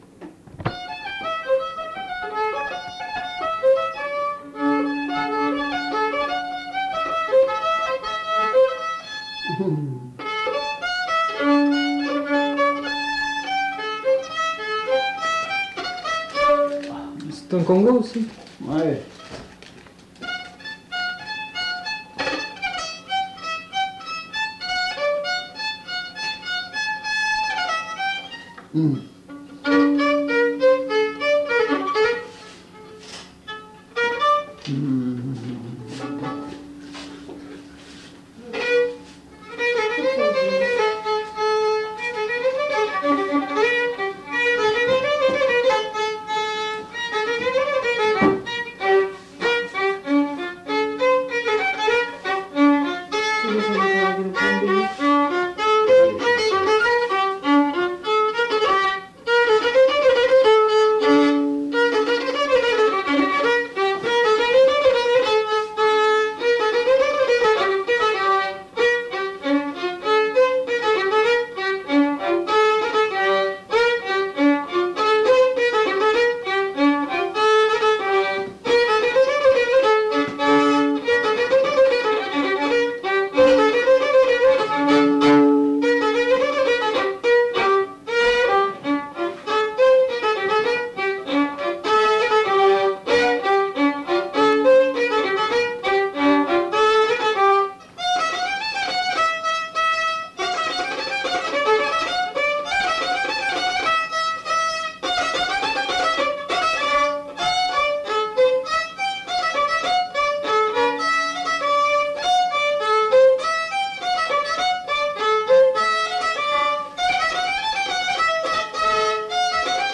Aire culturelle : Lugues
Lieu : Saint-Michel-de-Castelnau
Genre : morceau instrumental
Instrument de musique : violon
Danse : congo
Notes consultables : Deux thèmes enchaînés.